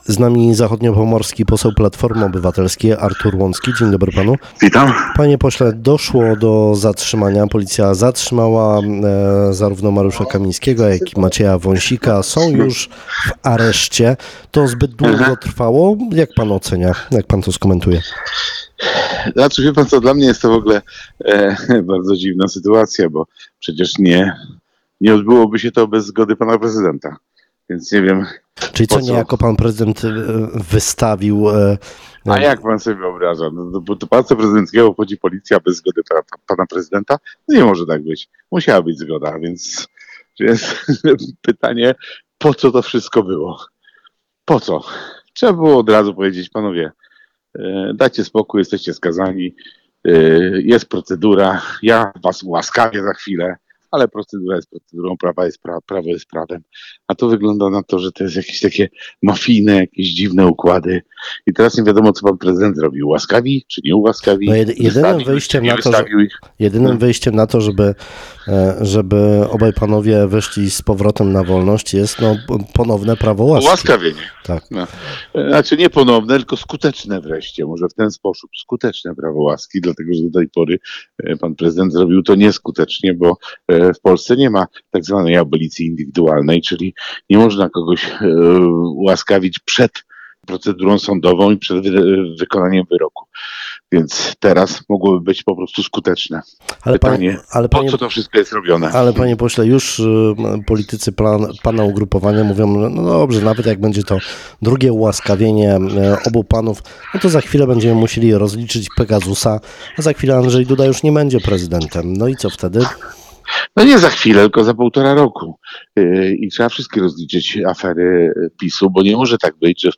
– Obrady sejmu są przełożone, ale mamy pierwsze posiedzenie naszego zachodniopomorskiego zespołu parlamentarnego. To jest bardzo bardzo ważne ciało. Mam także spotkanie u pana ministra Marchewki z rybakami rekreacyjnymi. Taka robota pozasejmowa, poza głosowaniami – mówi Artur Łącki, zachodniopomorski poseł Platformy Obywatelskiej.